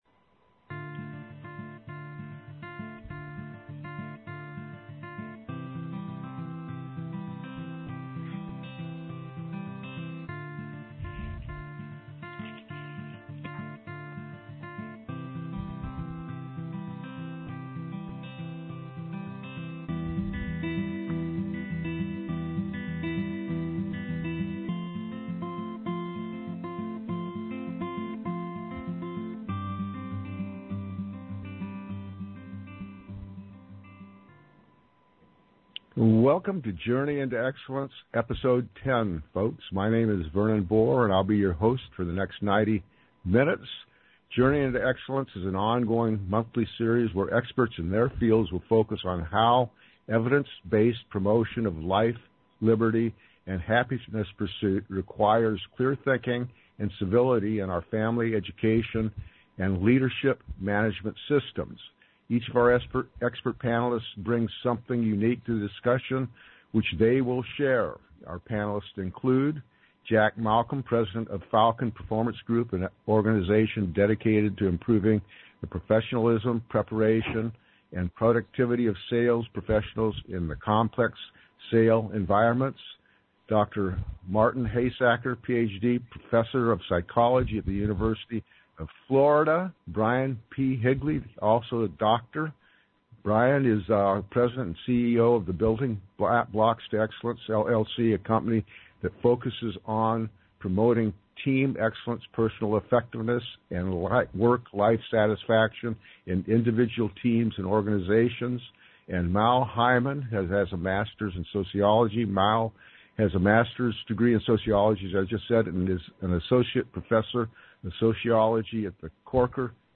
We recently completed the next episode of Journey Into Excellence (JIE), a radio podcast series focused on identifying and implementing evidence-based life, liberty, and happiness pursuit practices in our family, education, and leadership/management systems. The show features a panel of experts in leadership, management, sales, psychology, political science, personal effectiveness, and life satisfaction.